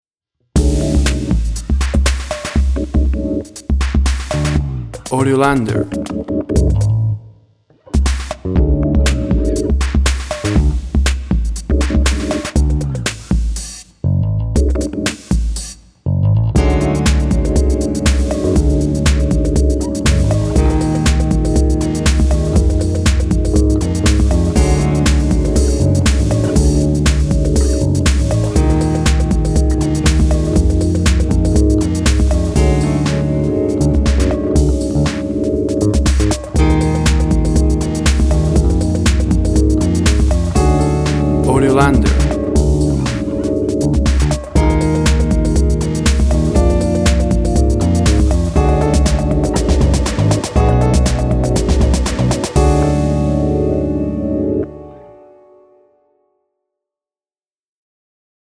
ROYALTY FREE MUSIC
Tempo (BPM) 120